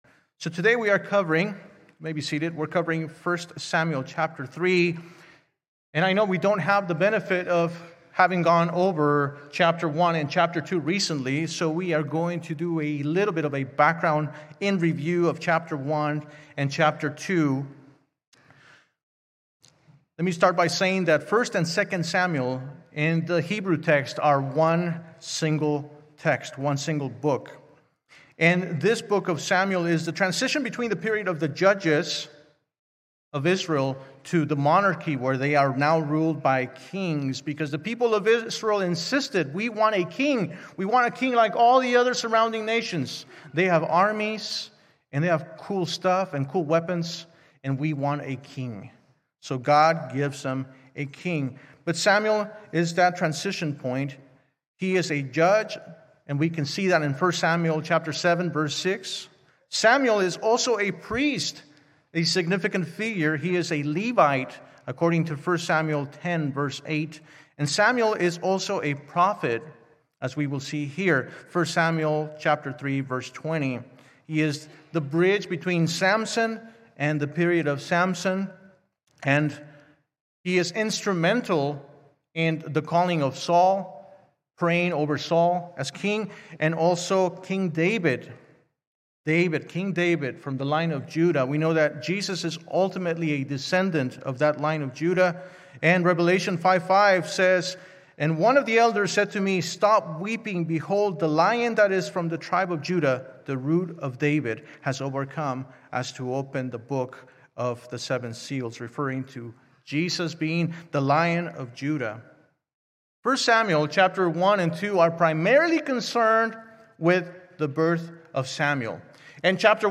A message from the series "Guest Speaker." 1.God Calls Samuel 1 Samuel 3:1-10 2.God Speaks To Samuel 1 Samuel 3:11-15 3.Samuel Speaks Truth 1 Samuel 3:16-18 4.God’s Favor Is With Samuel 1 Samuel 3:19-21
From Series: "Sunday Morning - 10:30"